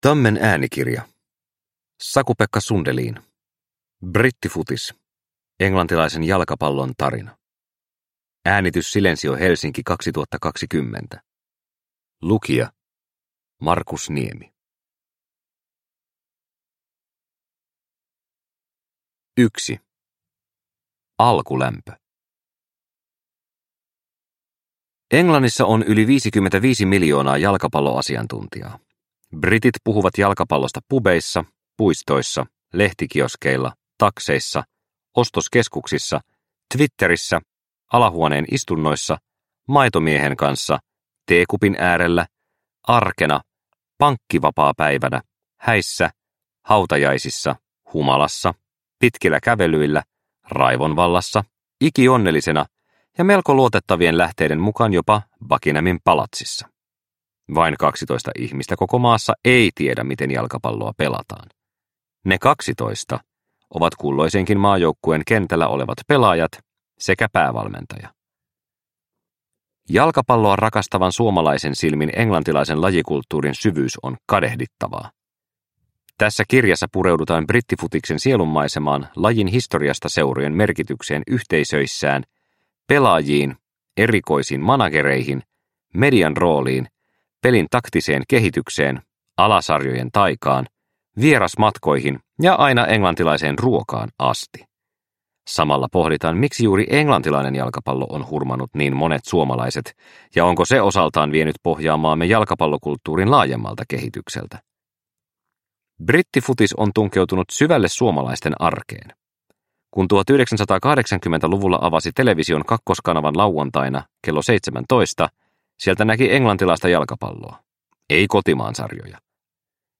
Brittifutis – Ljudbok – Laddas ner